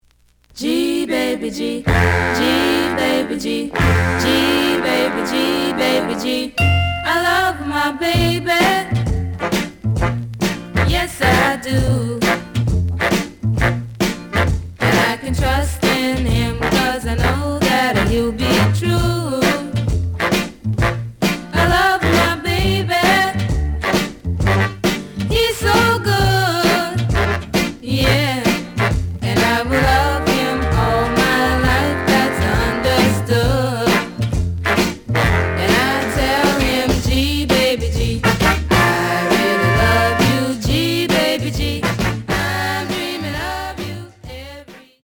The audio sample is recorded from the actual item.
●Genre: Rock / Pop
Some damage on both side labels. Plays good.)